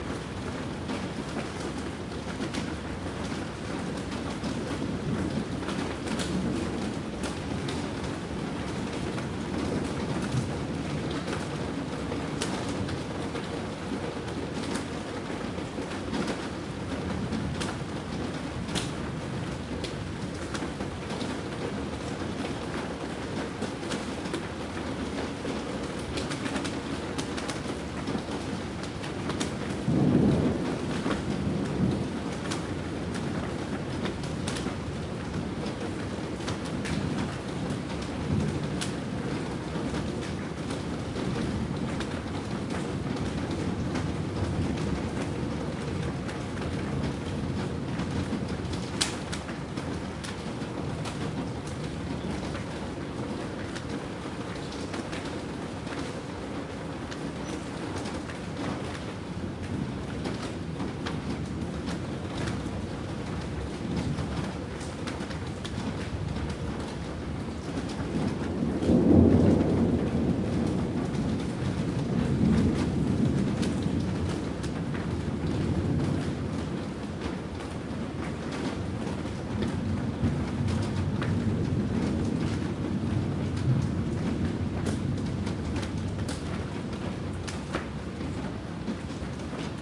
随机 "雨中的街道人行道
描述：在街道pavement.flac的雨媒介
标签： 街道 介质 路面
声道立体声